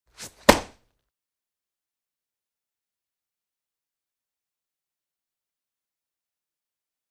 Cloth Zuzz And Fast Impact On Body